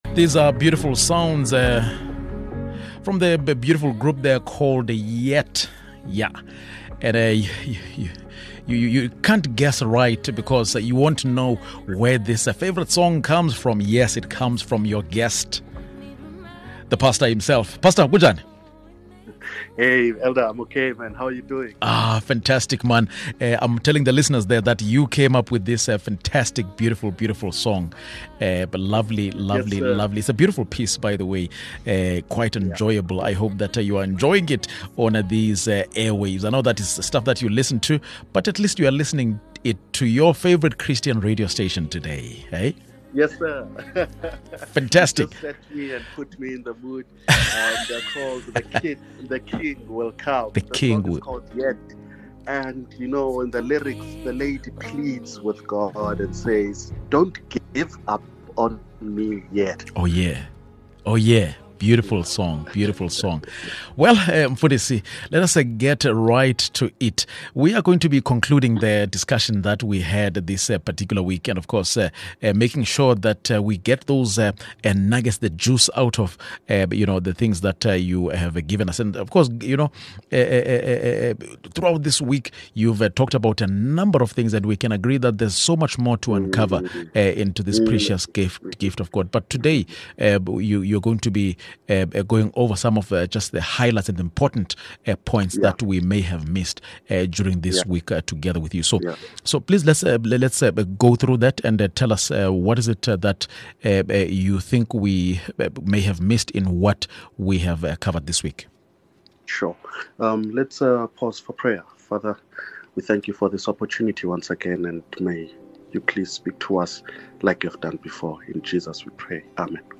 we’ve been talking about grace throughout this week, and we can all agree that there’s so much more to uncover about this precious gift of God. Today, our pastor will be going over some important points that we may have missed during our week together.